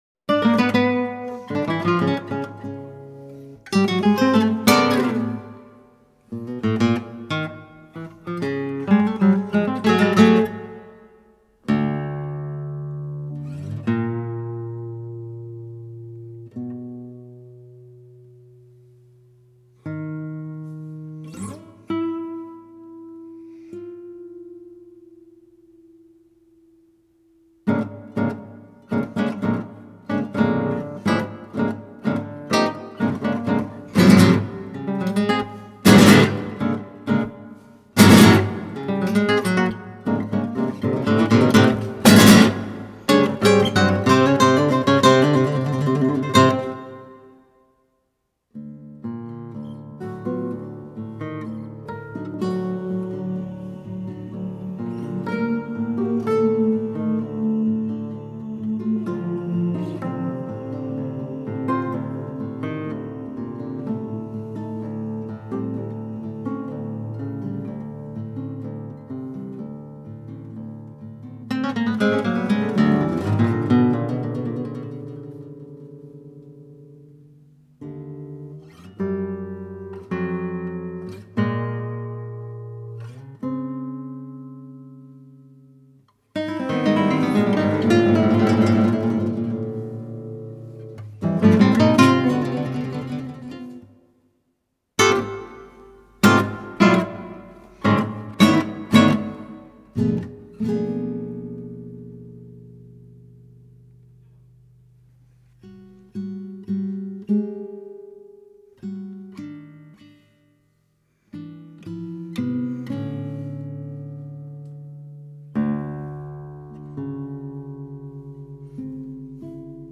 Trihuela para tres guitarras